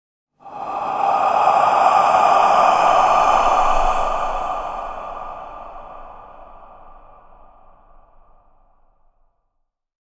Ghost Breath Sound Effect
A spooky ghost ahh sound echoes in the darkness. The haunting voice creates a chilling and eerie atmosphere.
Ghost-breath-sound-effect.mp3